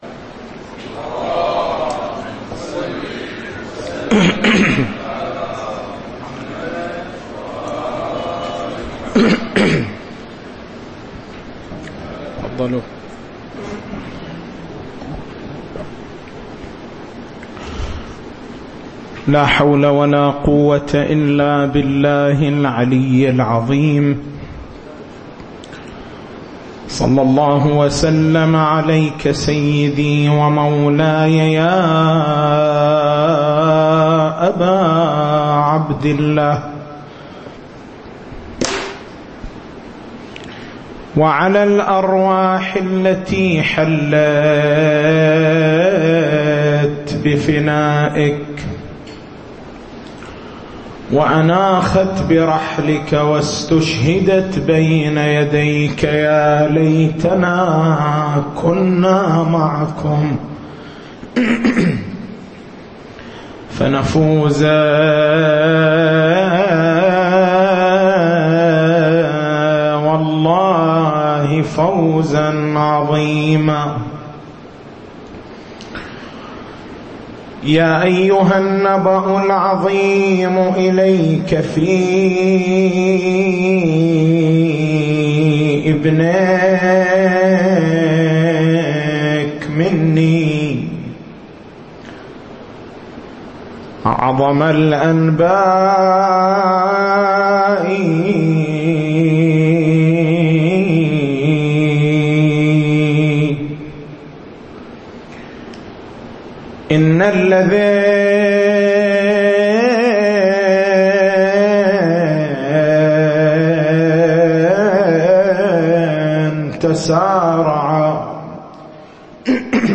تاريخ المحاضرة: 29/09/1436 نقاط البحث: بيان حقيقة الشفاعة ما هو مفهوم الشفاعة؟ هل الشفاعة لزيادة الثواب، أم لدفع العقاب؟